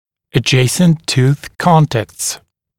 [ə’ʤeɪsnt tuːθ ‘kɔntækts][э’джейснт ту:с ‘контэктс]контактные точки соседних зубов